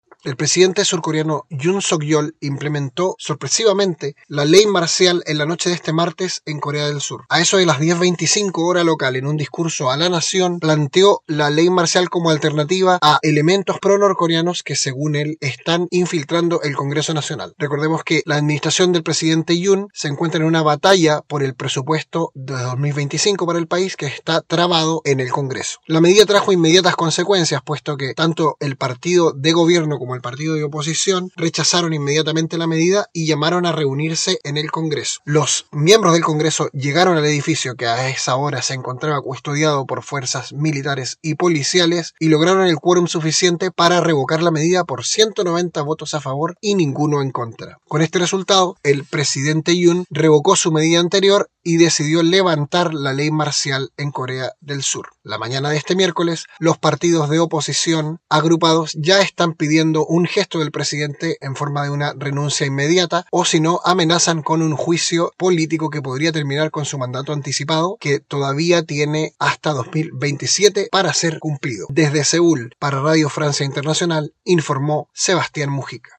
CORR_COREA.mp3